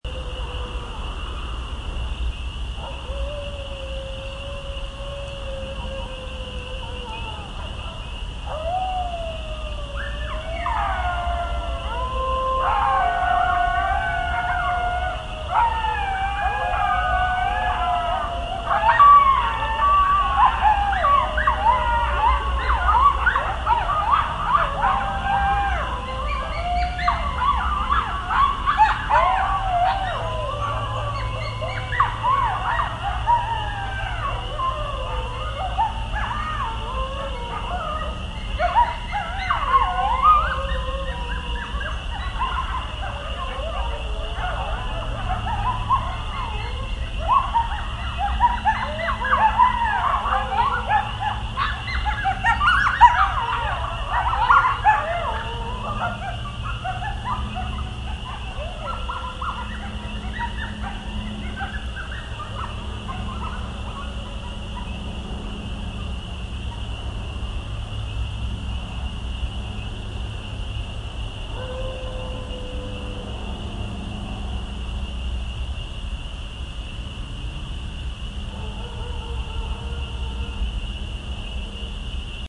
Coyotes Of Pelham 2 Bouton sonore